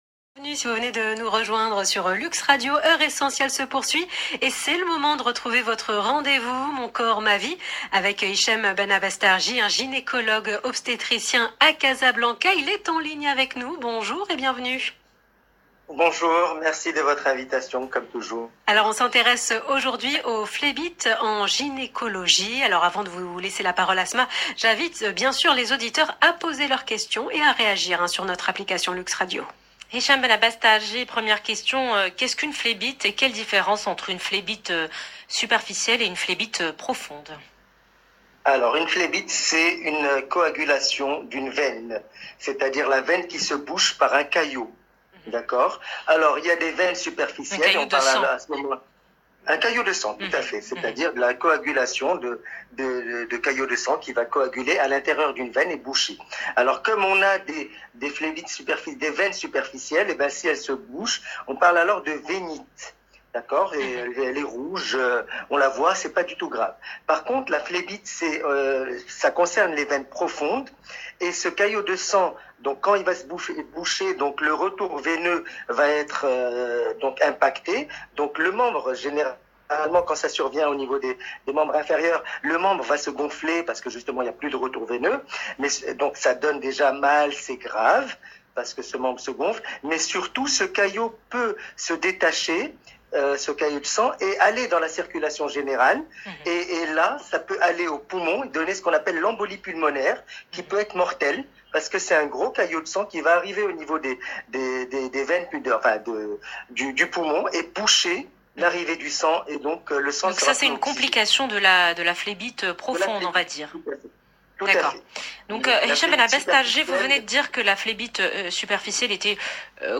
Essai de réponse au cours de cette interview dans l’Heure essentielle sur LUXE RADIO